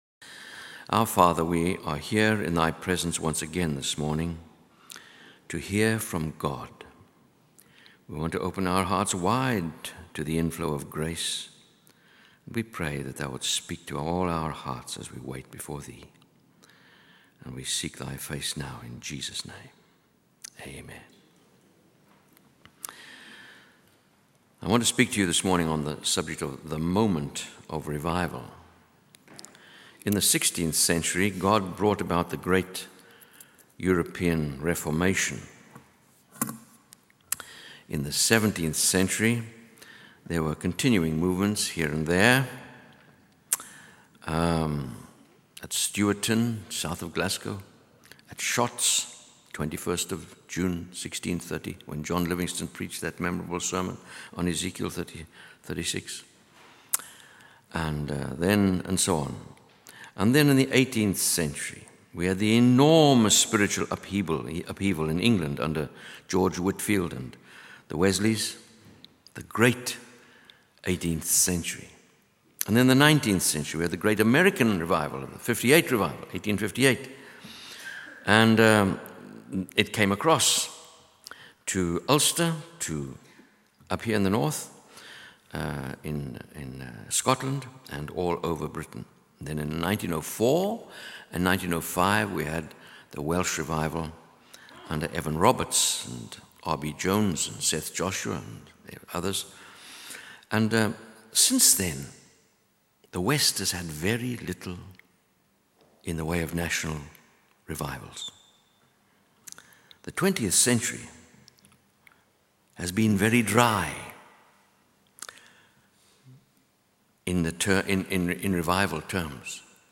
In this sermon, the speaker shares his experiences of witnessing the presence of God in meetings and conventions. He recounts a specific incident where he handed over the meeting to a Japanese interpreter who led the congregation in seeking God.